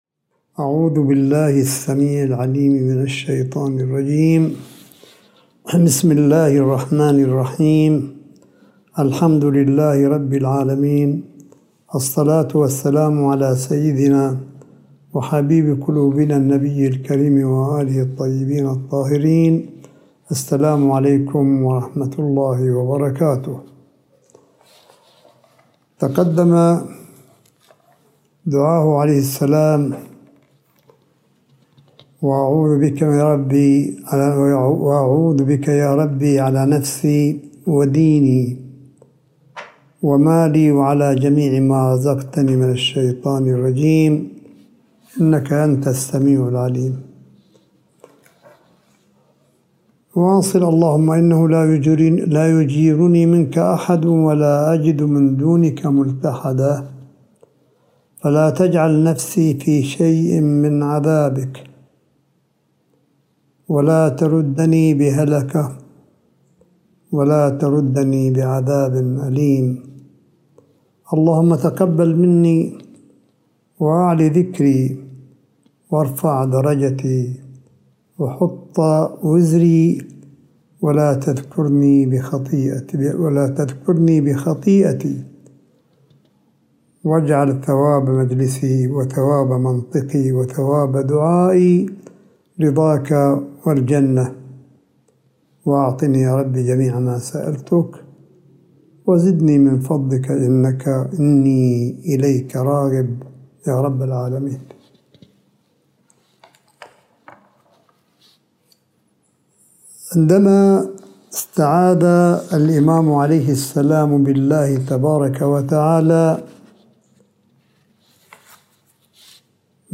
ملف صوتي للحديث الرمضاني (29) لسماحة آية الله الشيخ عيسى أحمد قاسم حفظه الله – 30 شهر رمضان 1442 هـ / 12 مايو 2021م (1)